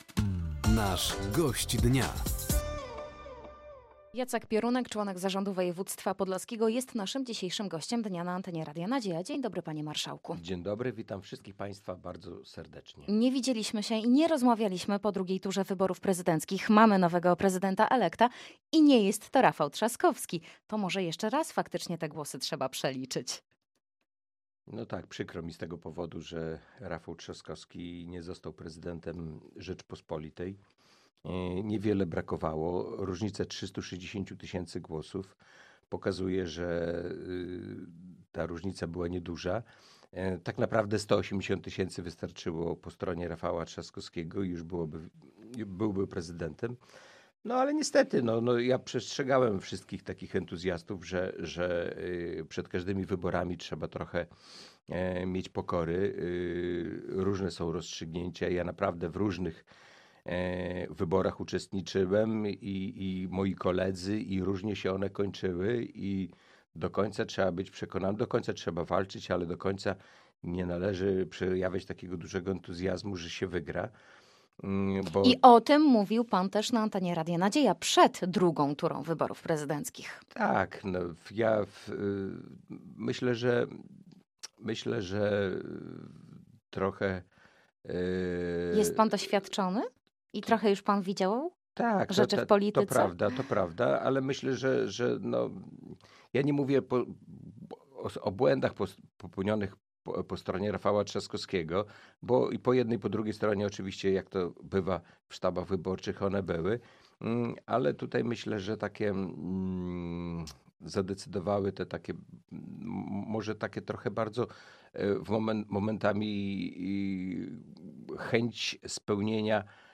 O przegranych wyborach przez Rafała Trzaskowskiego, udzieleniu absolutorium dla Zarządu Województwa czy współpracy między Powiatem Łomżyńskim, a powiatem Dingolfing—Landau w Bawarii – mówił dzisiejszy Gość Dnia Radia Nadzieja, którym był Jacek Piorunek, członek członek Zarządu Województwa Podlaskiego.